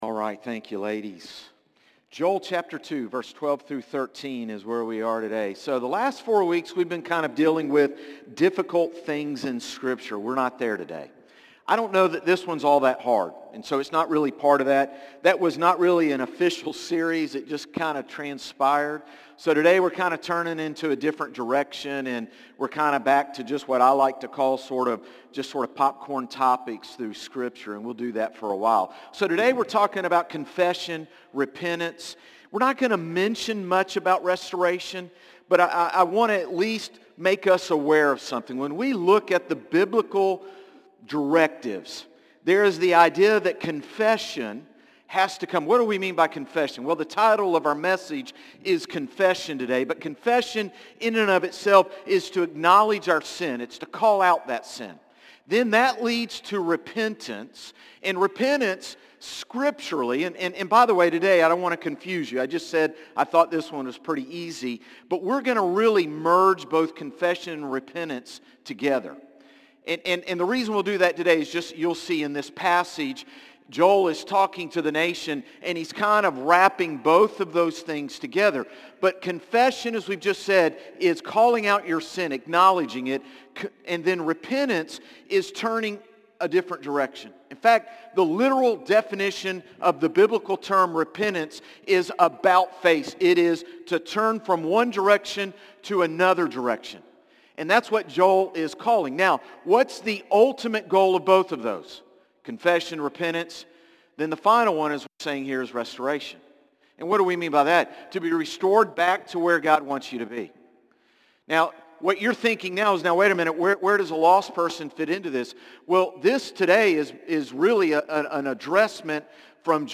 Sermons - Concord Baptist Church
Morning-Service-9-1-24.mp3